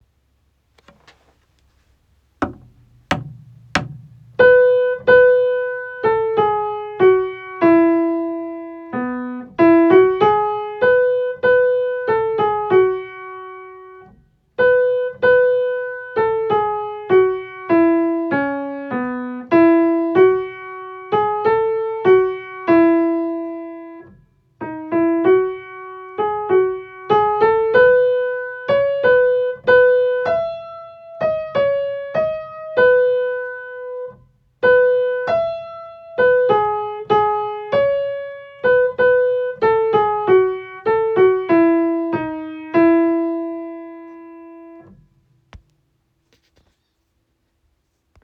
大正の校歌　メロディ